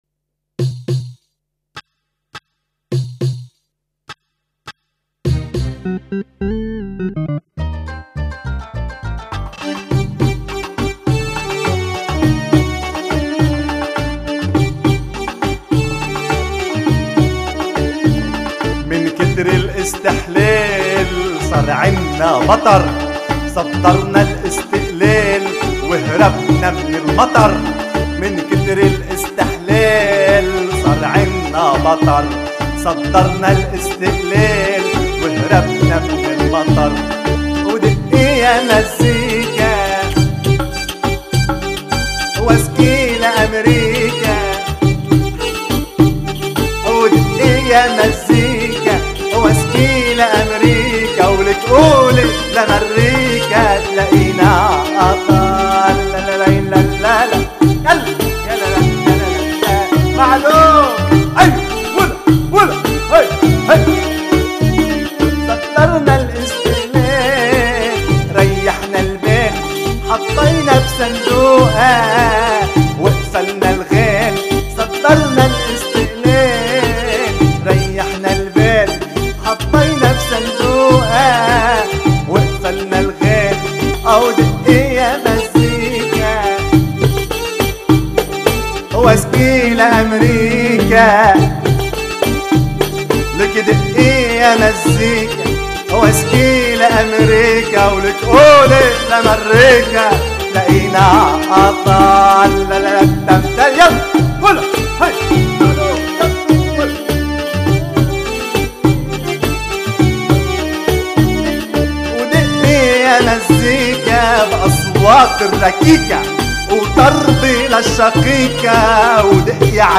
وسجلت وعزفت وخرجت LIVE في 23.11.2004